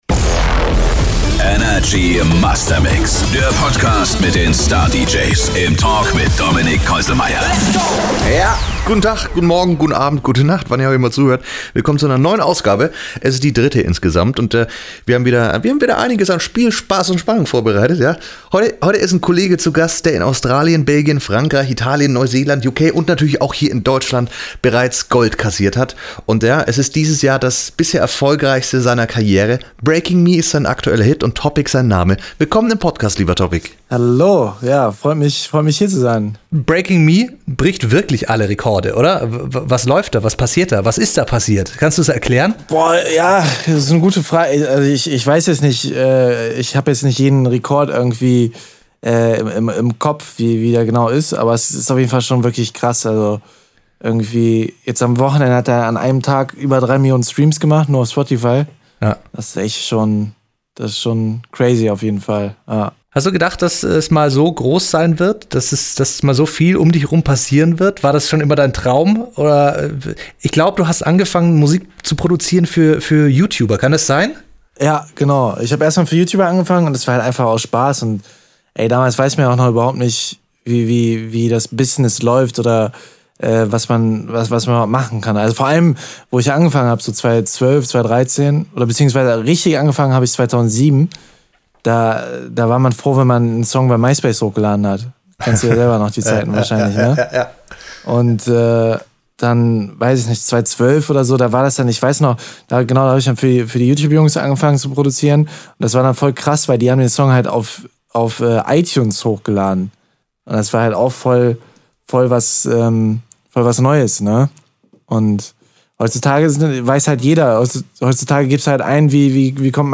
Bei einem der drei Themen ist Topic ein absoluter Experte. Die Storys zu seinen Erlebnisse und seiner Musik hört ihr im Talk